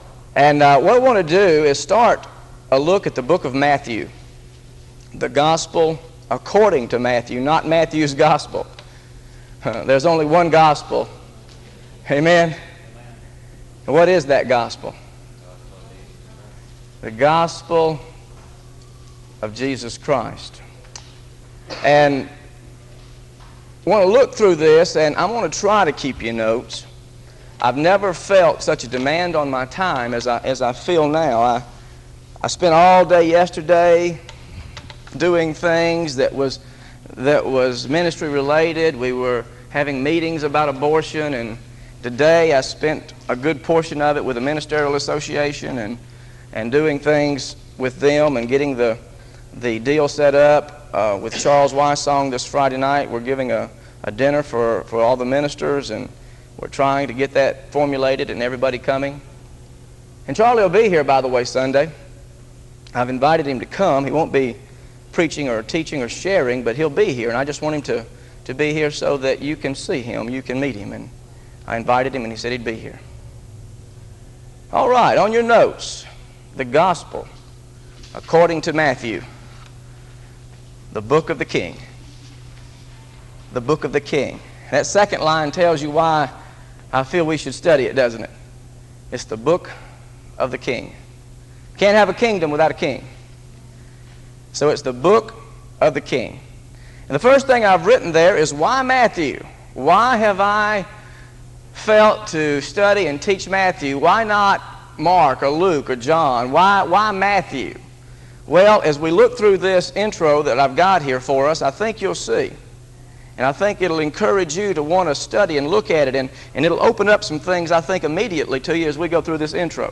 Gospel of Matthew Study Series This study of Matthew – audio and notes is part of a verse-by-verse teaching series through the Gospel of Matthew.